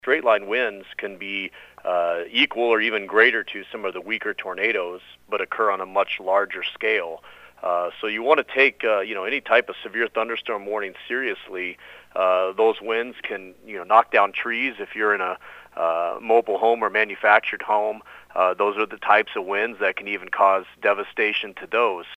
News Brief